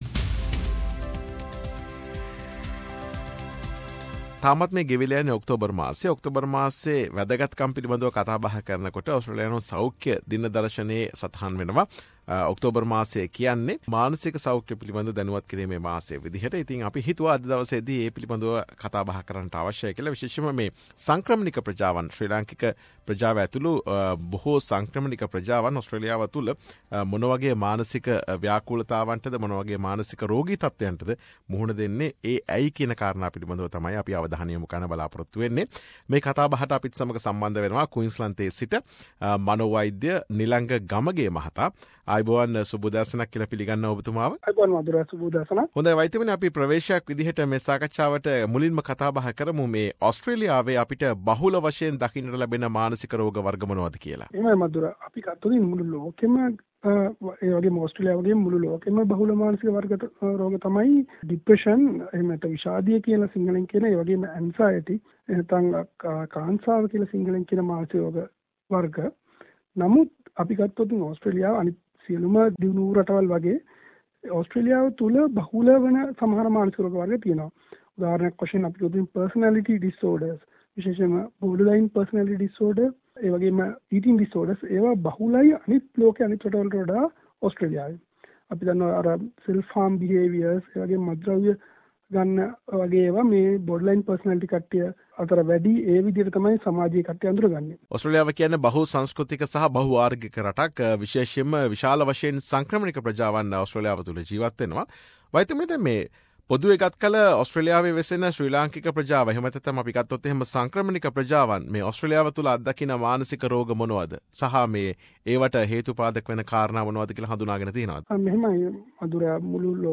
SBS සිංහල විදුලිය සිදු කළ සාකච්ඡාව.